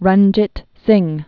(rŭnjĭt sĭng) Known as "the Lion of the Punjab." 1780-1839.